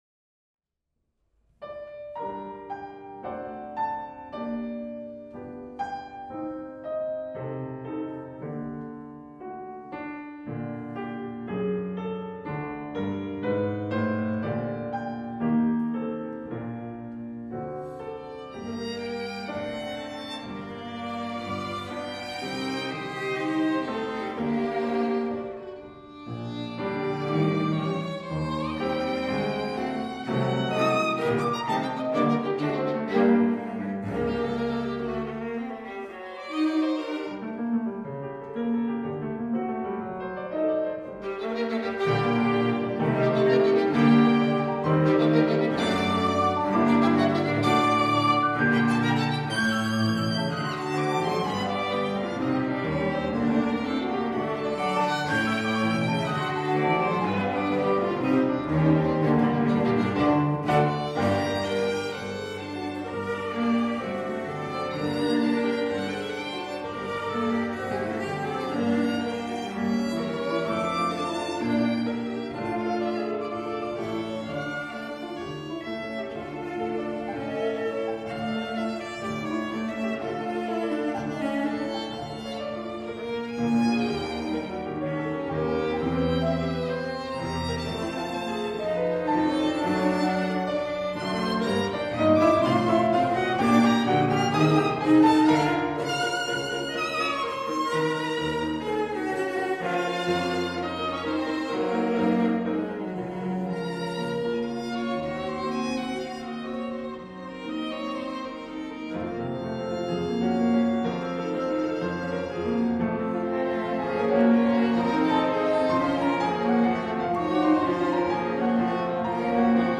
Soundbite 1st Movt
It opens with a lovely Schubertian Allegro non troppo.